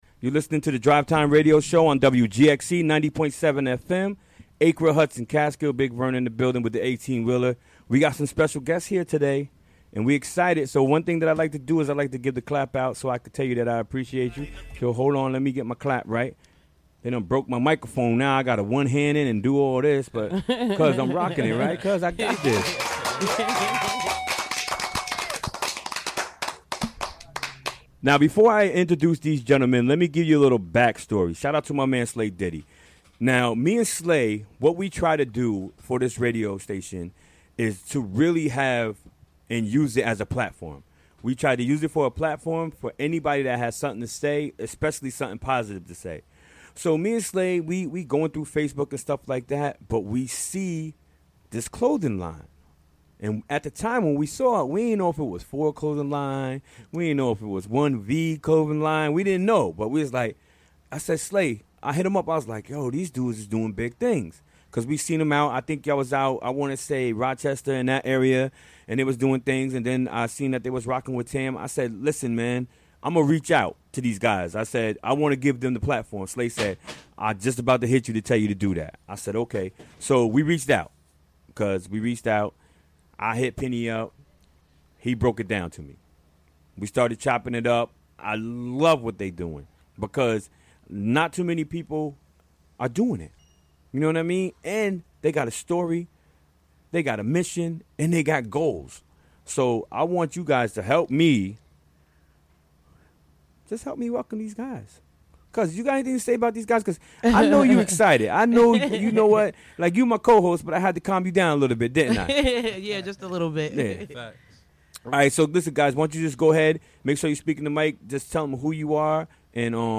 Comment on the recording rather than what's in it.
Recorded during the WGXC Afternoon Show on Wednesday, September 6, 2017.